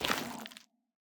Minecraft Version Minecraft Version snapshot Latest Release | Latest Snapshot snapshot / assets / minecraft / sounds / block / sculk / step2.ogg Compare With Compare With Latest Release | Latest Snapshot
step2.ogg